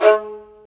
Index of /90_sSampleCDs/Roland LCDP13 String Sections/STR_Violas Marc/STR_Vas3 Marcato